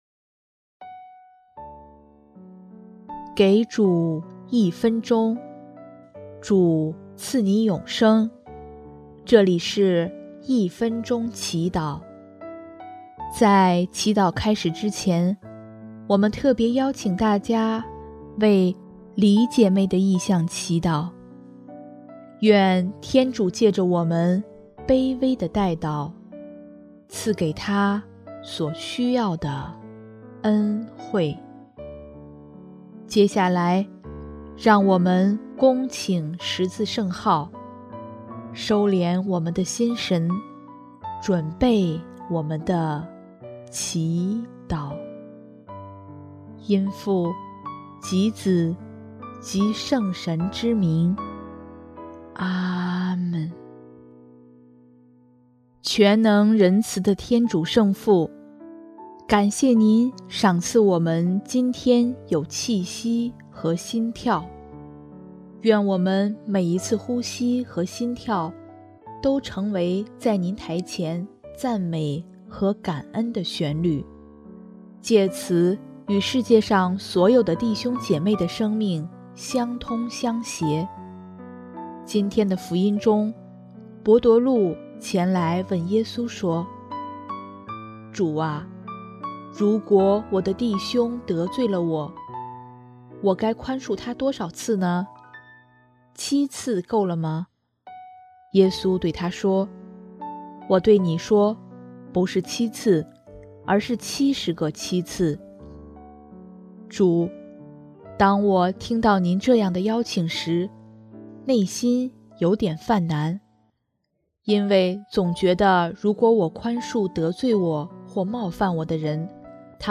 首页 / 祈祷/ 一分钟祈祷